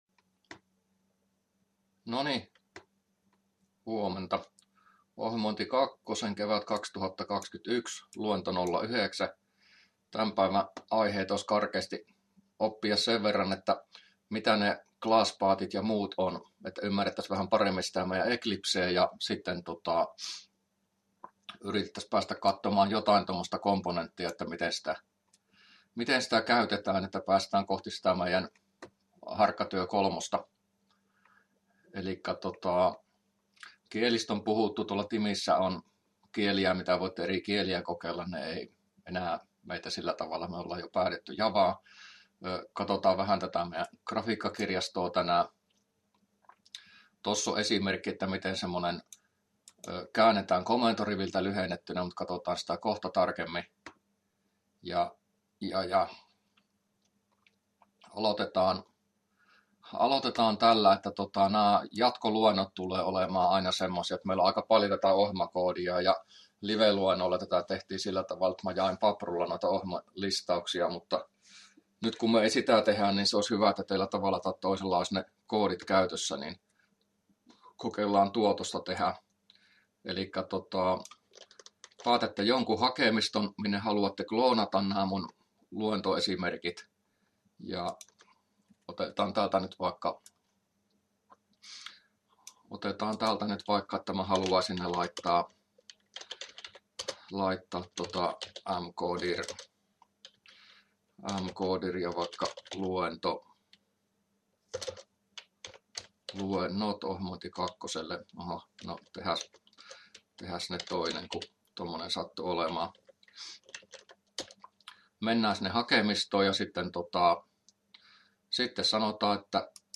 luento09a